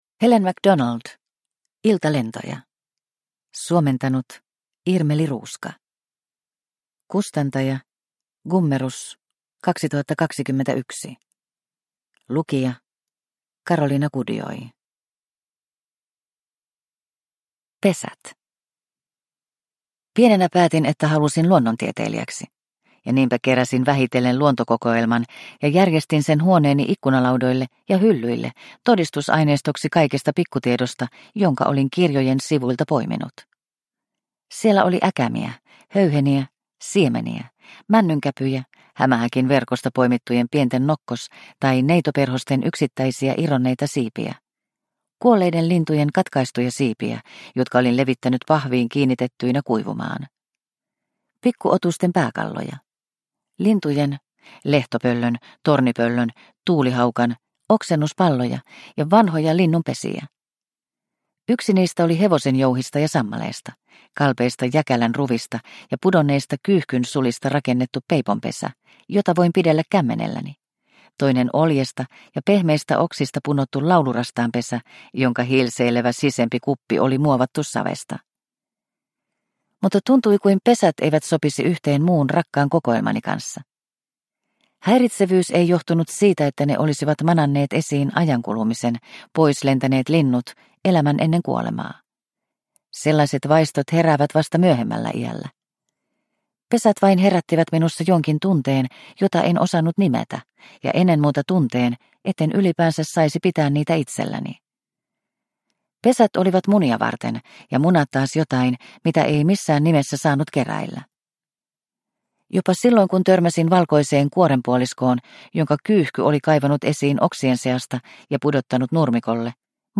Iltalentoja – Ljudbok – Laddas ner